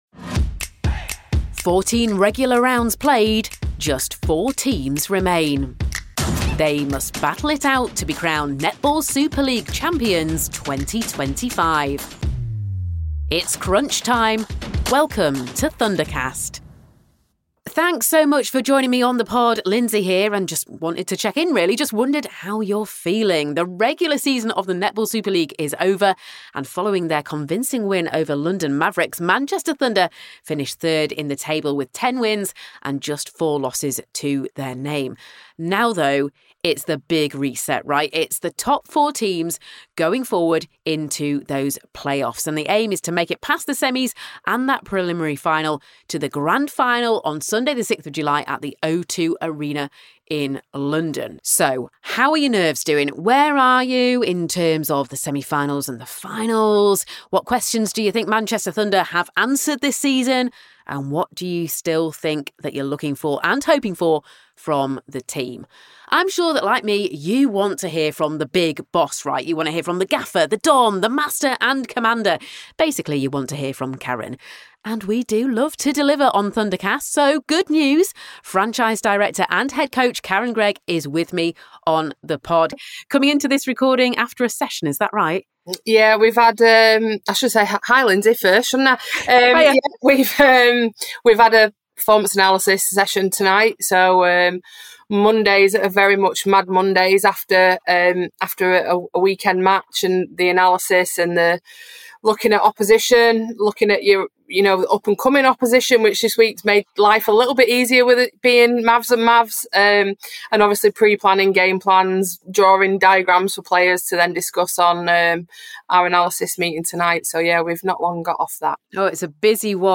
Access All Areas interview